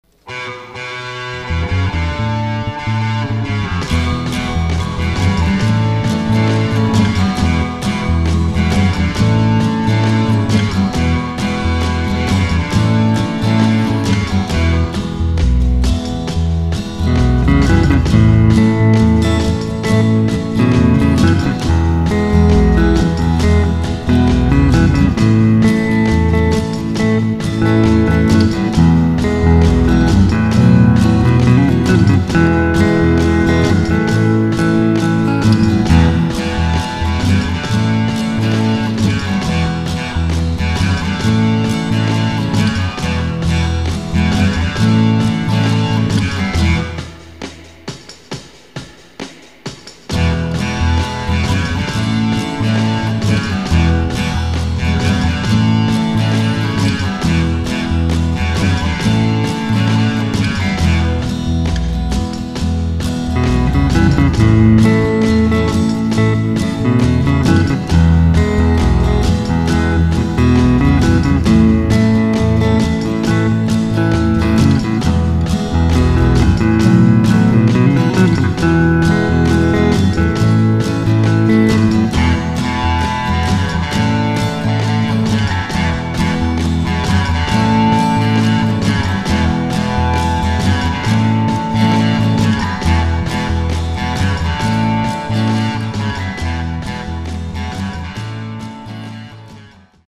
• - une guitare saturée
• - une guitare rythmique acoustique
• - une basse
• - une batterie